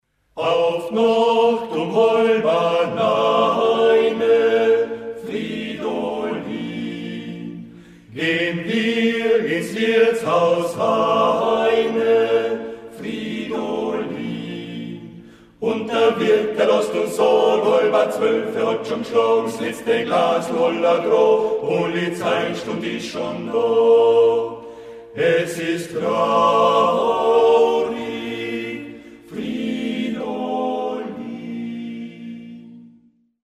• Aufgenommen im März 2005 in der Volksschule Poggersdorf
Volksweise) Kleingruppe